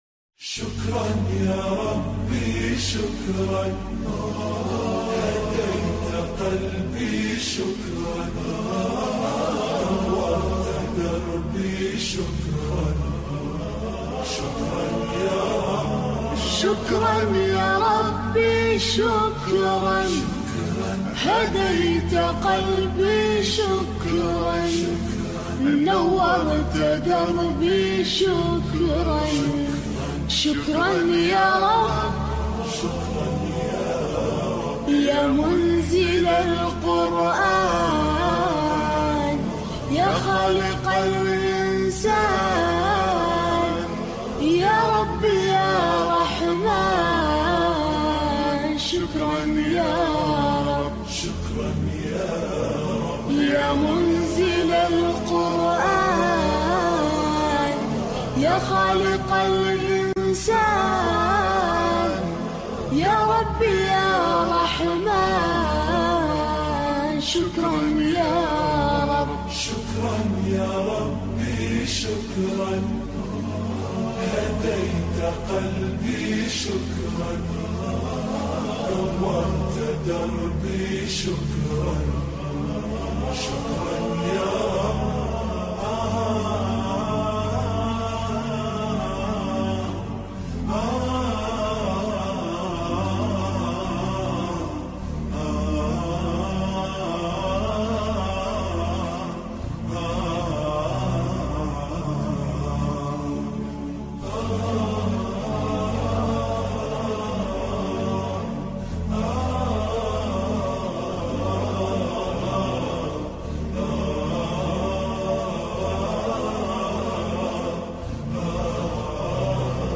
Listen to this beautiful Islamic Nasheed.